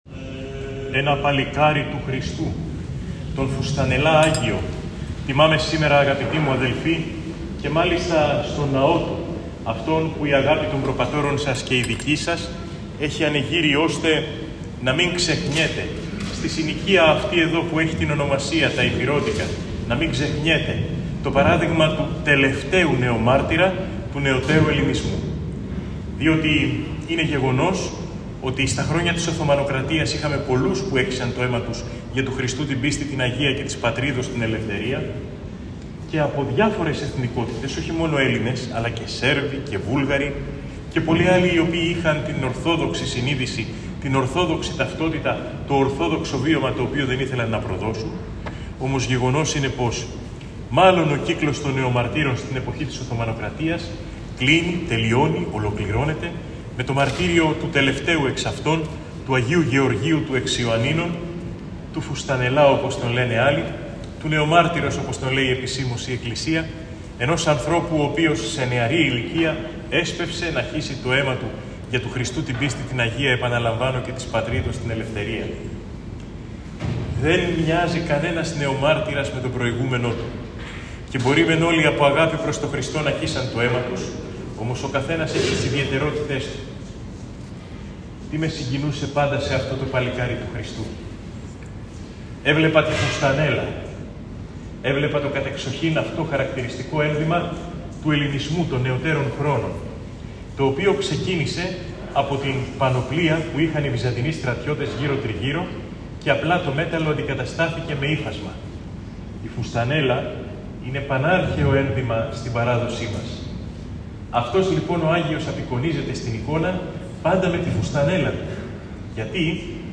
Στην εορτάζουσα ενορία του Αγίου Νεομάρτυρος Γεωργίου, του εν Ιωαννίνοις, στα Ηπειρώτικα Λάρισας, μετέβη ο Σεβασμιώτατος Μητροπολίτης Λαρίσης και Τυρνάβου κ. Ιερώνυμος το απόγευμα της Πέμπτης 16 Iανουαρίου 2025 και χοροστάτησε στον Εσπερινό της πανηγύρεως.